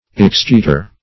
Excheator \Ex*cheat"or\, n.